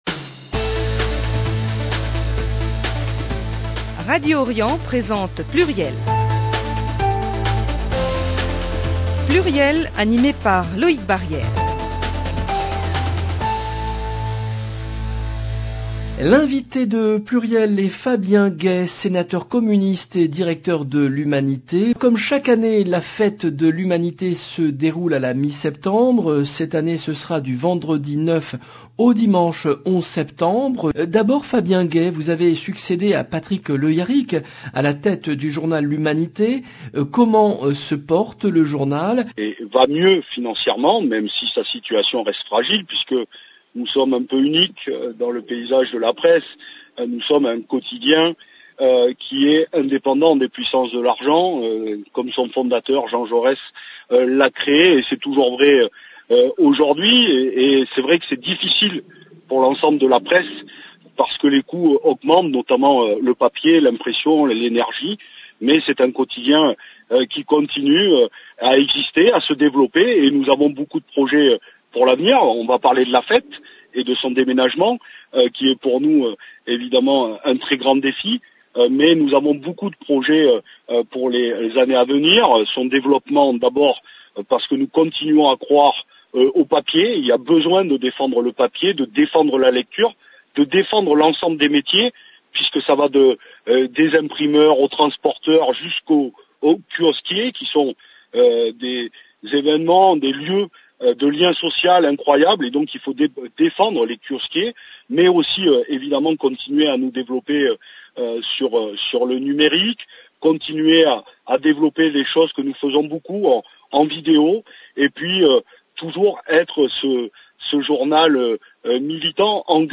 Fabien Gay, sénateur communiste et directeur de l’Humanité
Un entretien accordé à l’occasion de la fête de l’Humanité qui se déroule ce week-end.